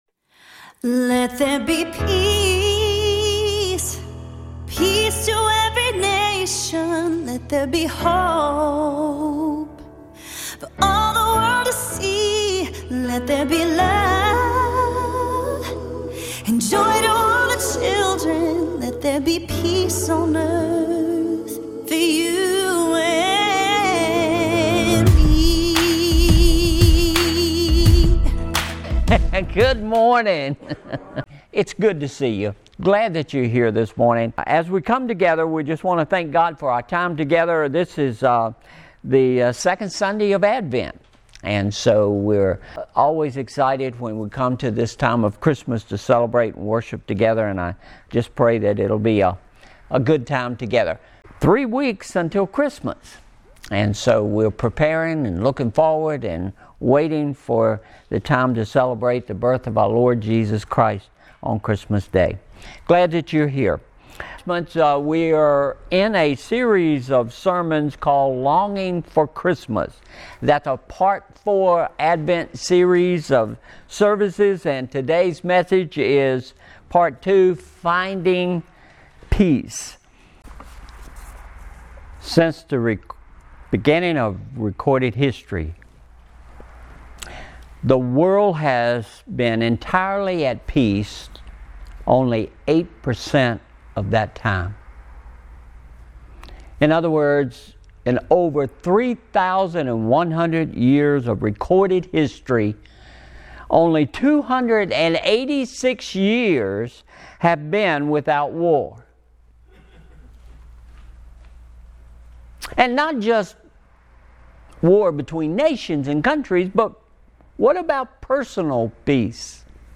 Finding Peace | Boones Mill Church of the Brethren